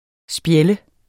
Udtale [ ˈsbjεlˀə ]